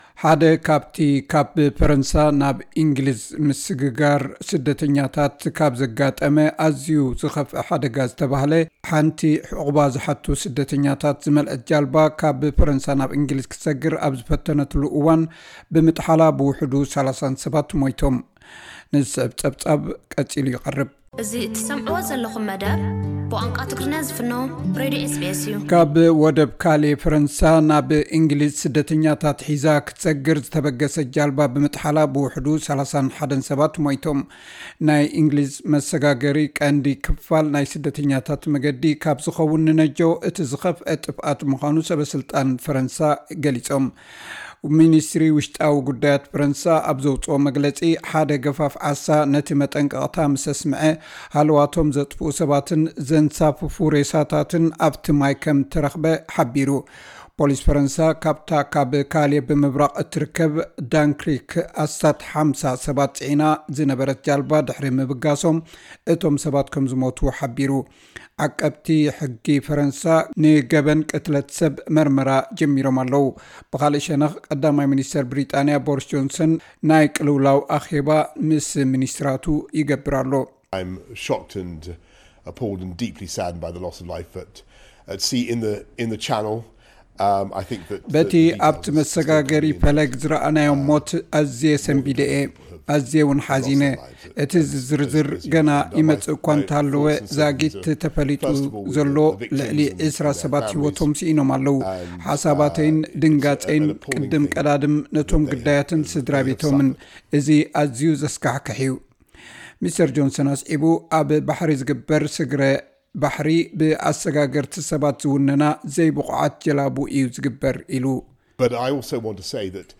ካብ ፈረንሳ ናብ እንግሊዝ ብጃልባ ዝሰግሩ ዝነበሩ ስደተኛታት ጃልባኦም ብምጥሓላ ልዕሊ 30 ሰባት ሞይቶም። (ጸብጻብ)